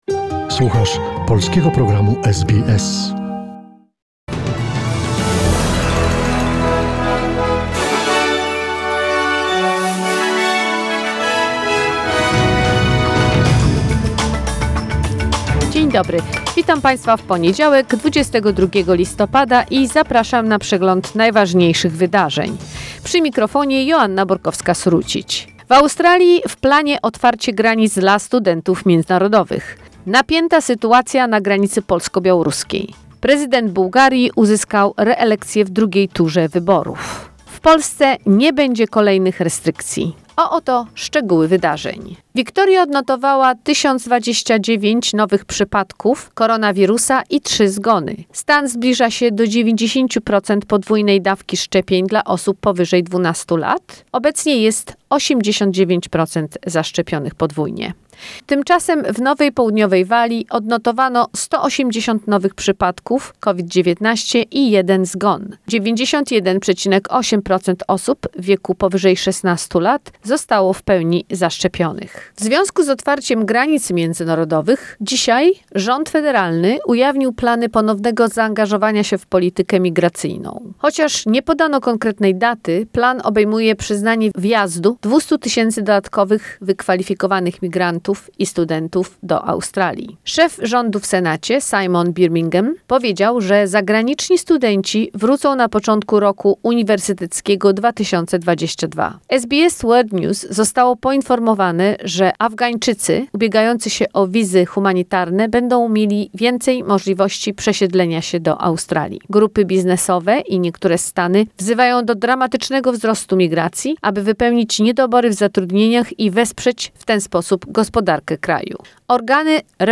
Wiadomości SBS, 22 listopada 2021